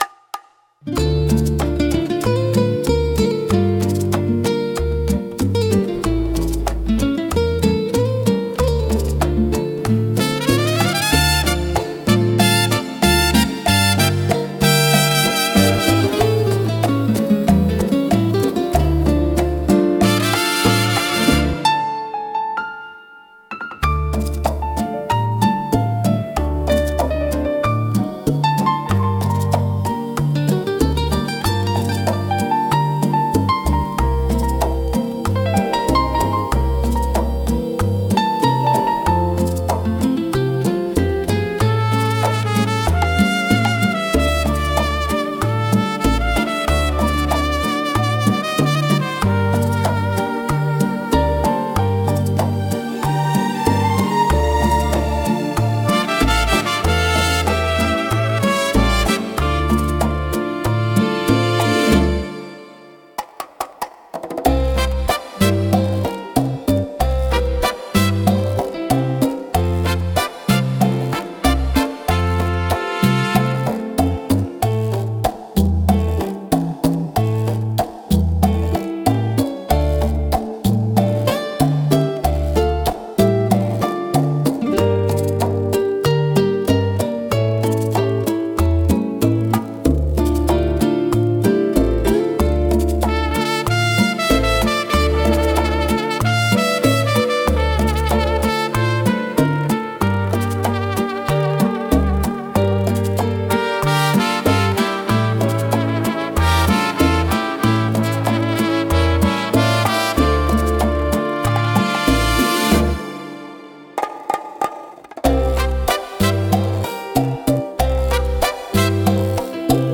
música e arranjo: IA) instrumental 8